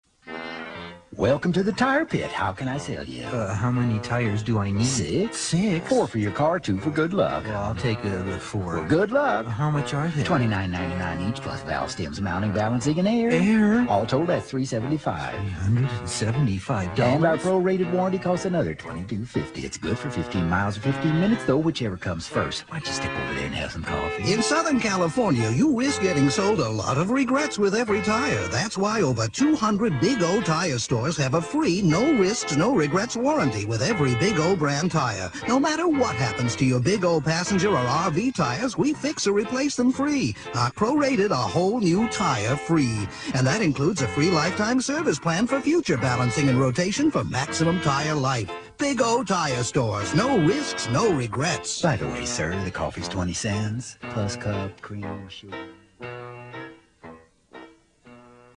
Various Radio Commercial Work